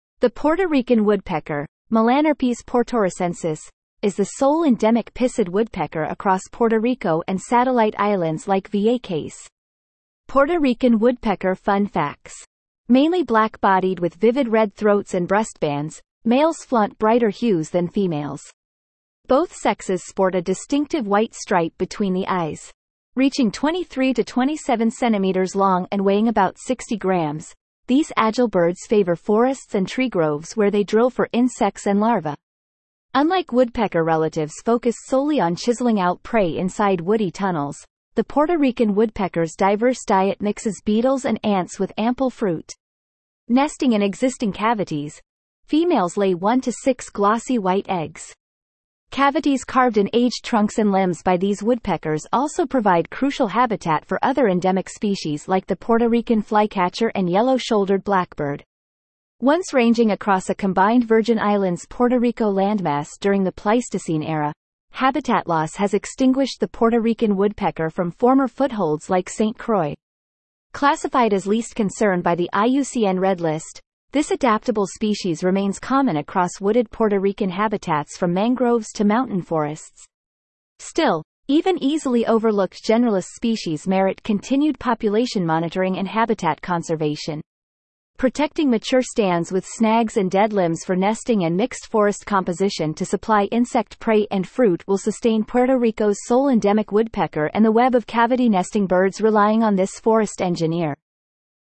Puerto Rican Woodpecker
Puerto-Rican-Woodpecker.mp3